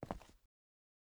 Concrete Walk - 0007 - Audio - Stone 07.ogg